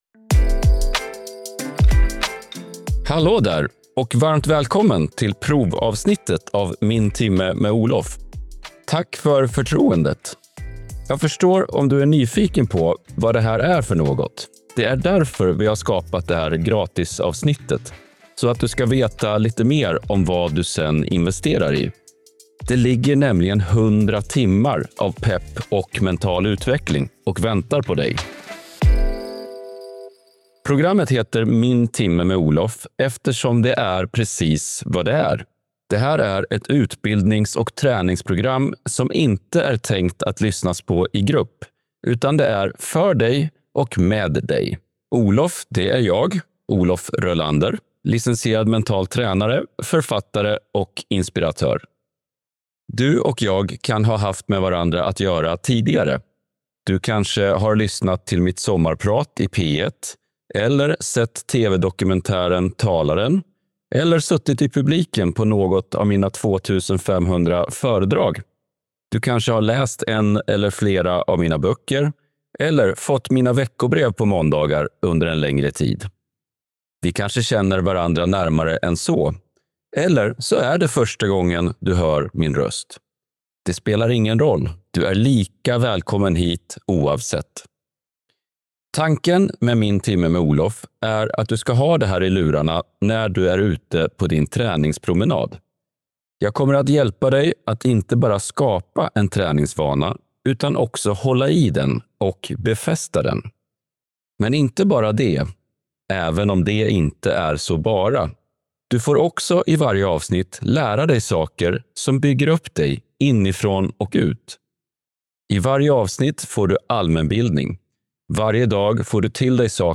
Varje avsnitt är 60 minuter långt och består av reflektioner, berättelser, forskning, musik och konkreta tips som gör skillnad.